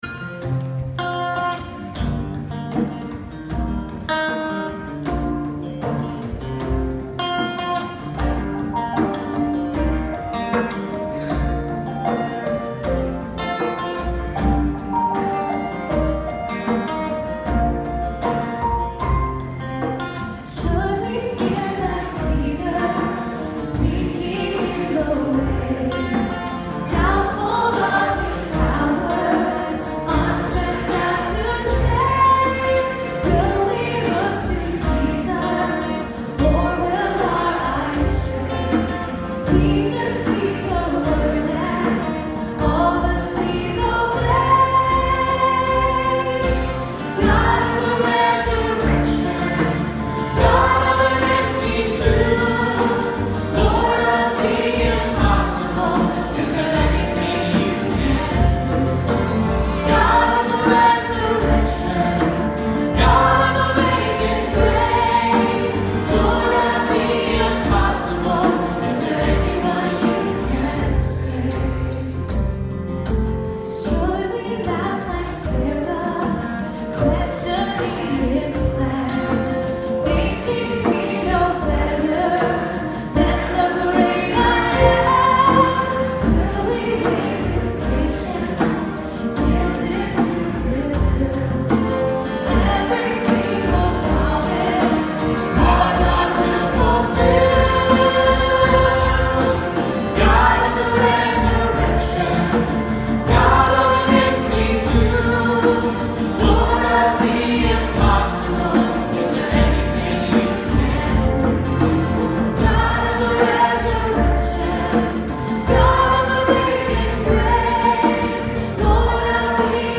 God of the Resurrection (LIVE)